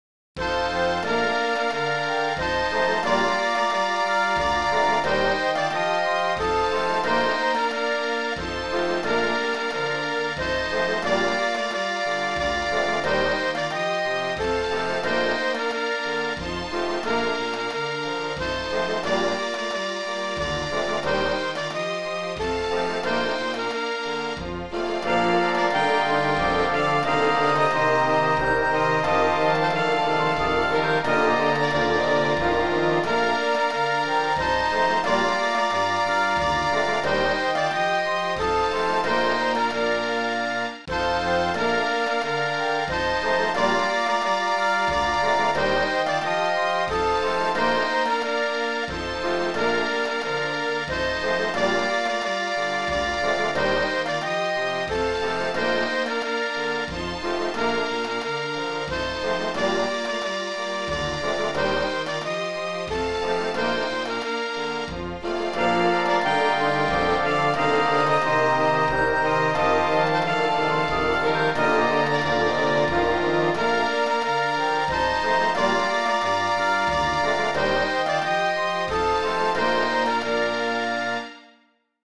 Full Orchestra: Oh Christmas Tree sax solo (24 Nov) 1:22